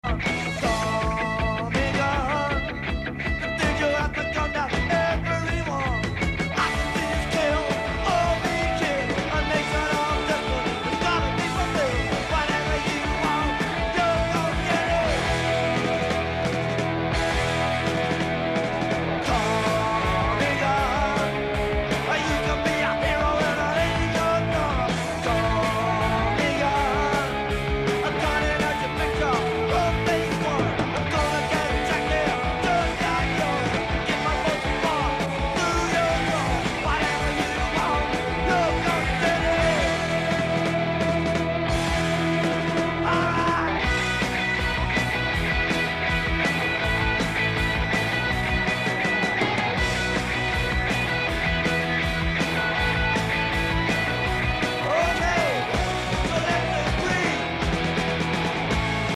A blistering punk track